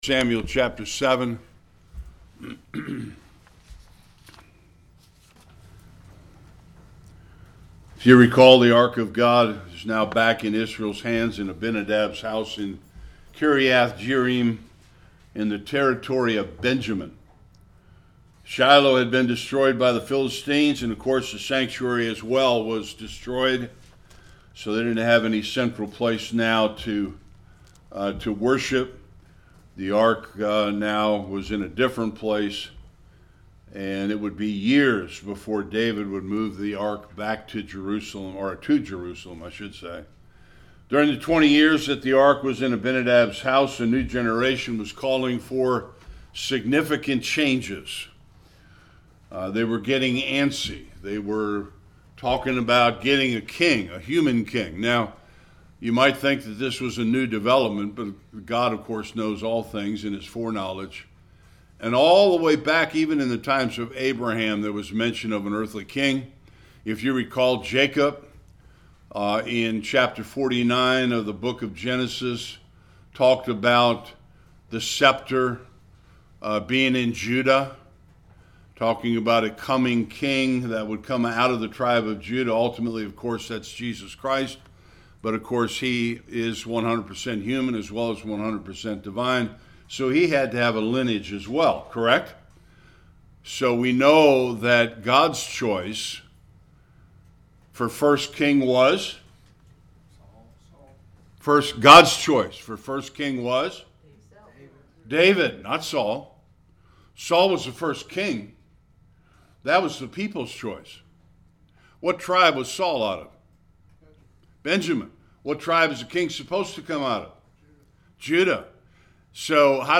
1-22 Service Type: Sunday School Israel rejects God as King and asks for an earthly king.